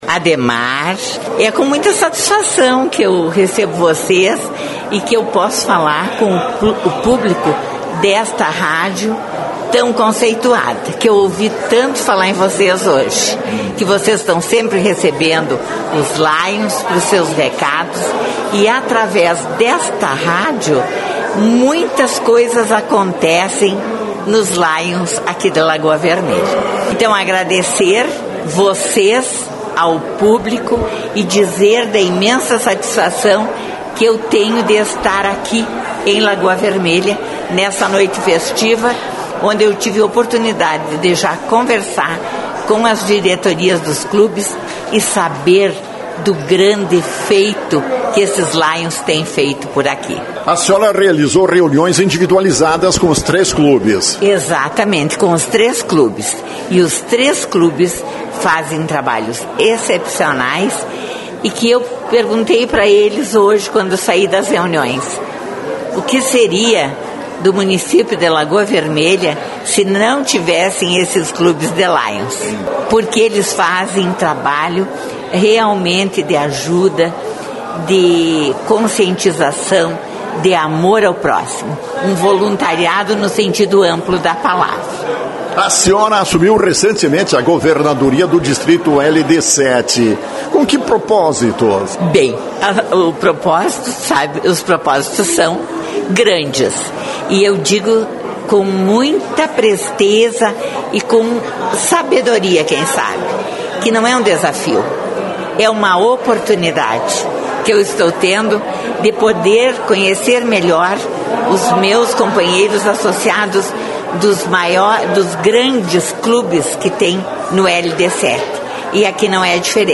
Inteirou-se sobre as ações de cada clube. Elogiou a atuação de todos eles. Foi ouvida pela Rádio Lagoa FM.